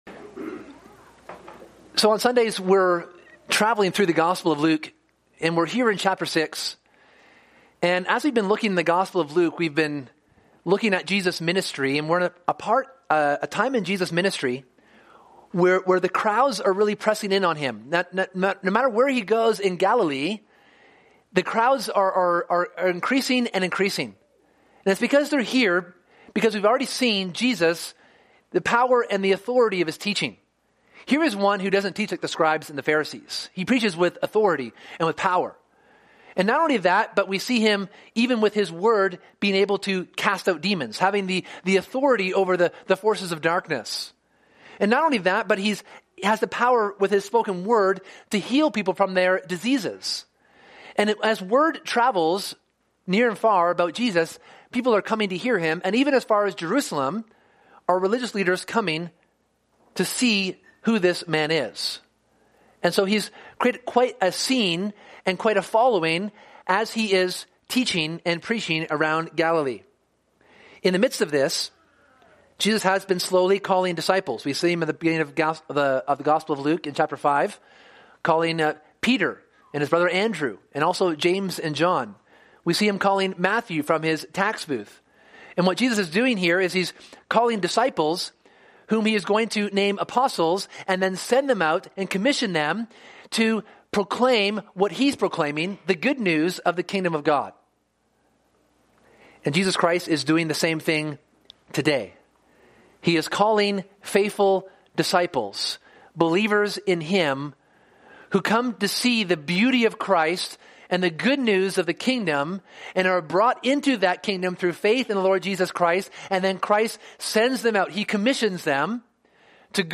This sermon looks at how Jesus calls twelve to be his apostles.